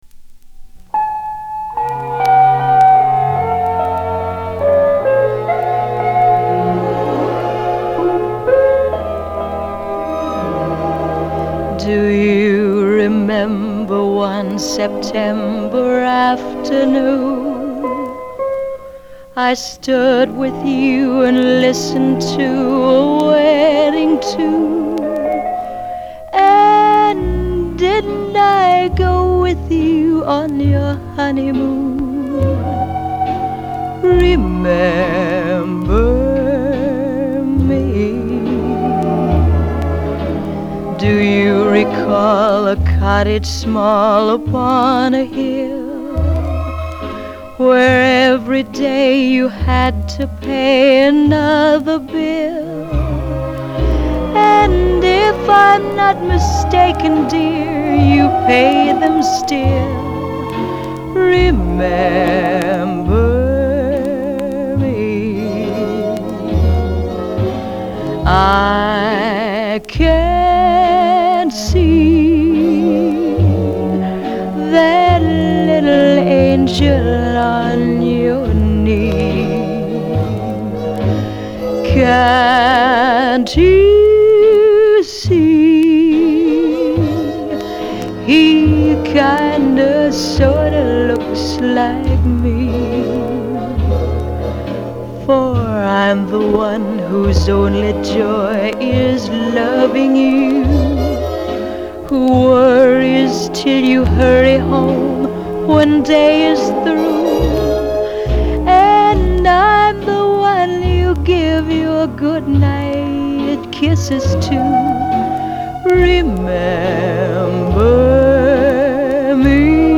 형식:레코드판, LP, Album, Mono
장르:Pop 1955.